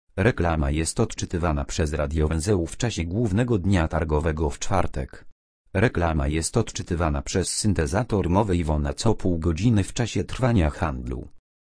Dźwiękowa
Reklama dźwiękowa jest odczytywana przez radiowęzeł w czasie głównego dnia targowego
Reklama jest odczytywana przez syntezator mowy IVONA co pół godziny w czasie trwania handlu od godziny 8.00 do 13.00 (czyli 11 razy)